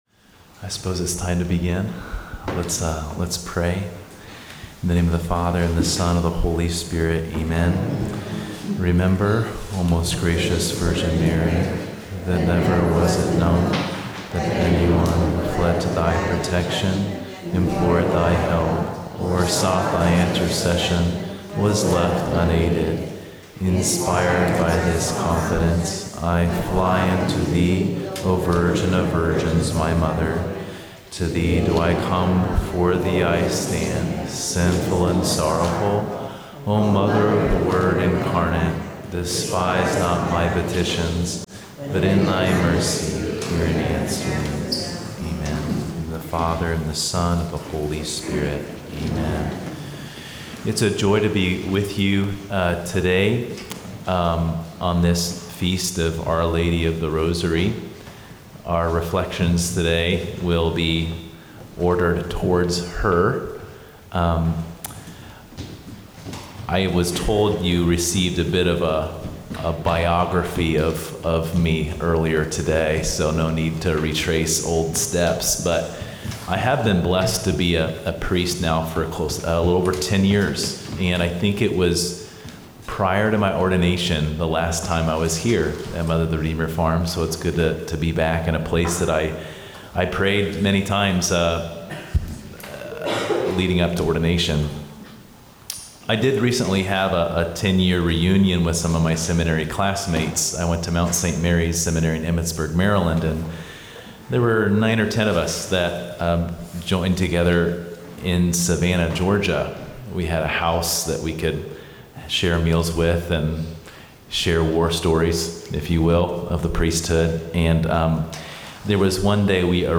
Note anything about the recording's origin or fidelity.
gives the first talk for our Fall Marian Day of Reflection on the power of the intercession of the Virgin Mary